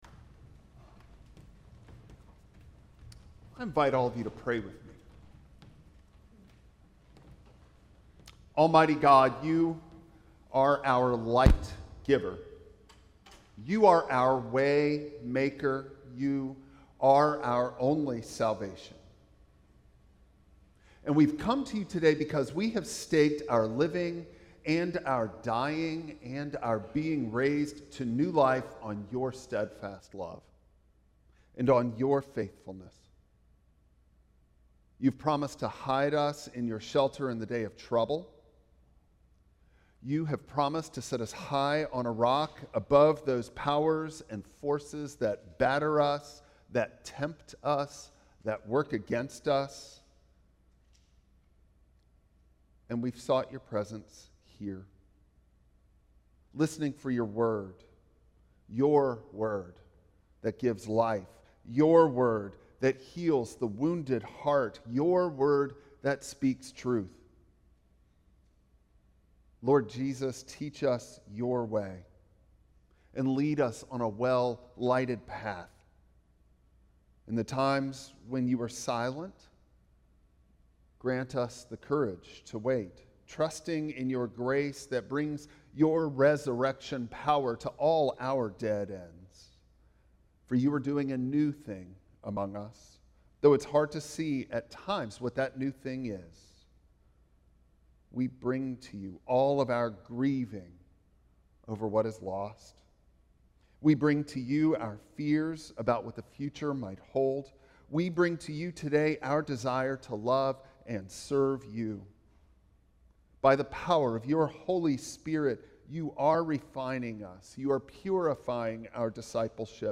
Passage: Nehemiah 4:1-15 Service Type: Traditional Service Bible Text